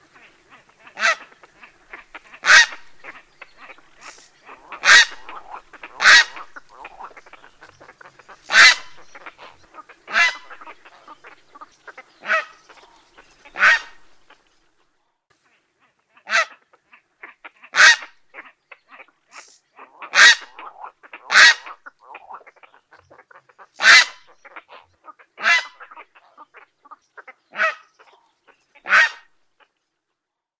heron-cendre.mp3